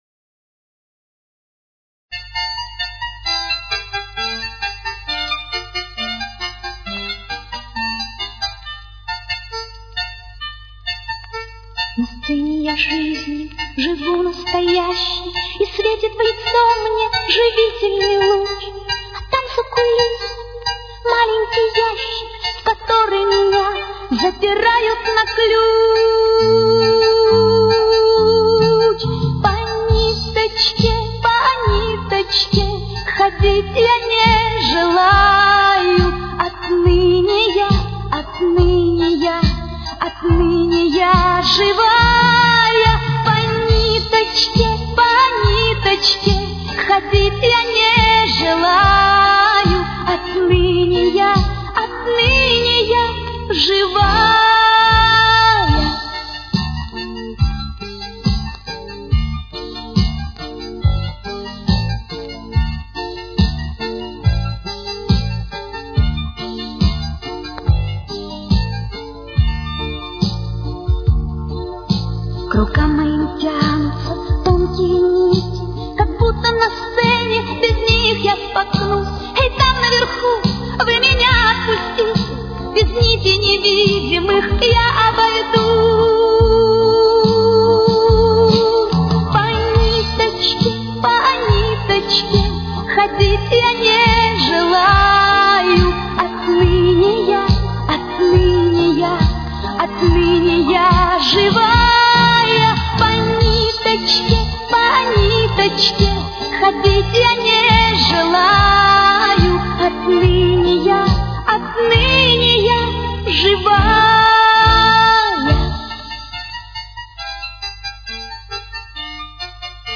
с очень низким качеством (16 – 32 кБит/с)
Темп: 140.